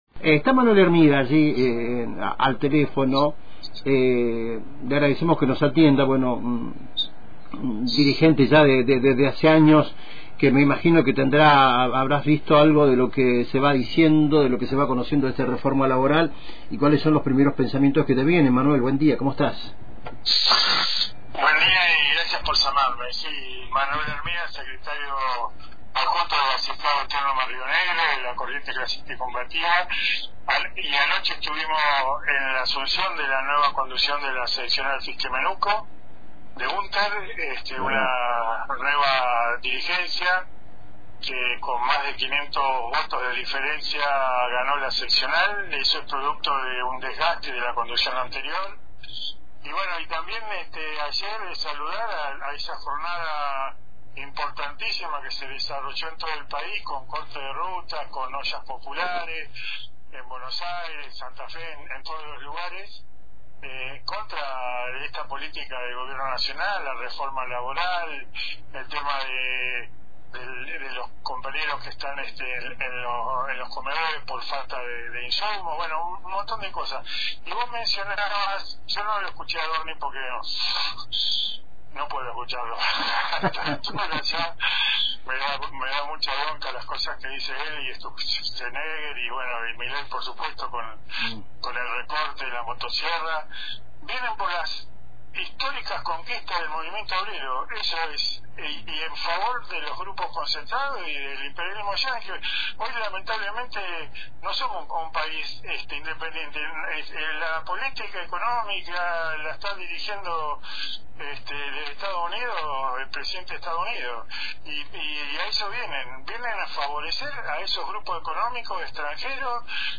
En diálogo con Antena Libre